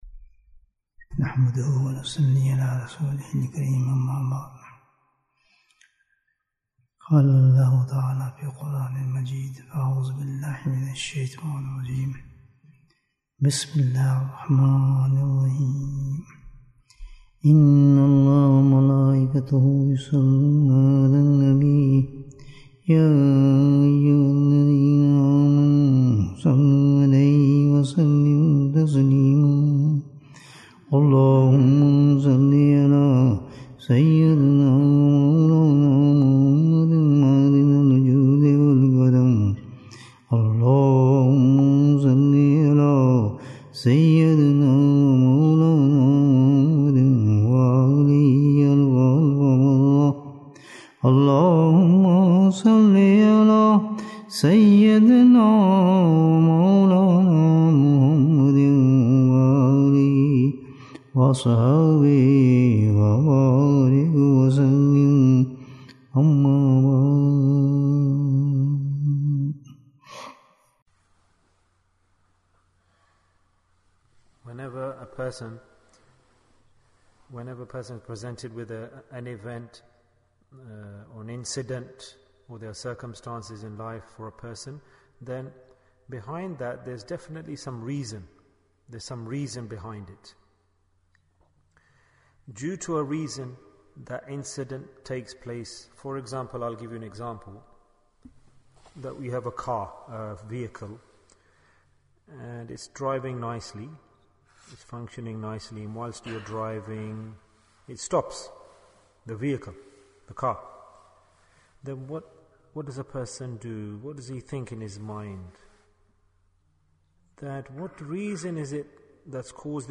Why Are We Weak in Deen? And It's Cure Bayan, 81 minutes 9th March, 2023 Click for Urdu Download Audio Comments Muhabbat محبت م = میں (I) حب - (love) ت = تو (you) Beautiful! 12th Mar, 2023 JazaakAllah khair hazrat sahib.